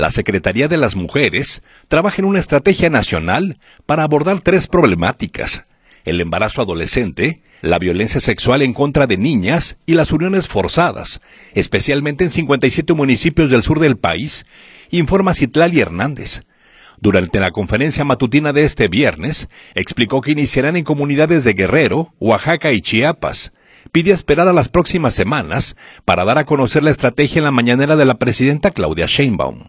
La Secretaría de las Mujeres trabaja en una estrategia nacional para abordar tres problemáticas: el embarazo adolescente, la violencia sexual en contra de niñas y las uniones forzadas, especialmente en 57 municipios del sur del país informó Citlalli Hernández. Durante la conferencia matutina de este viernes, explicó que iniciarán en comunidades de Guerrero, Oaxaca y Chiapas.